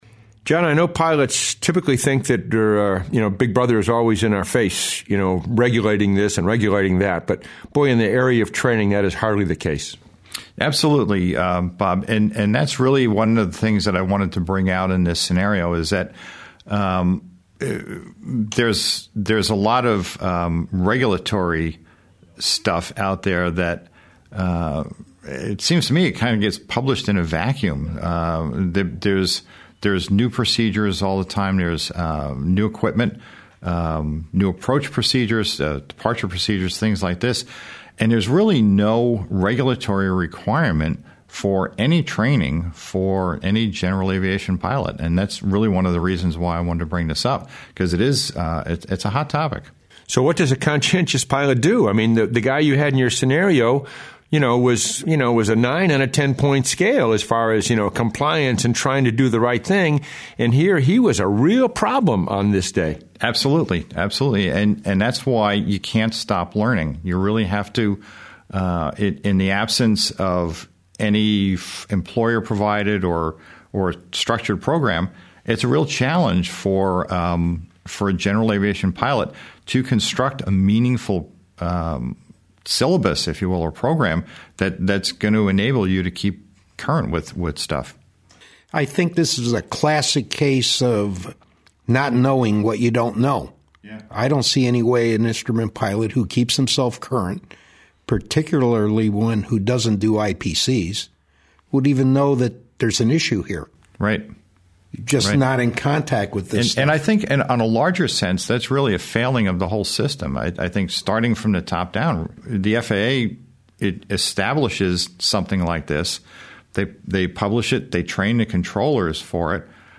74_Climbing_out_of_houston_roundtable.mp3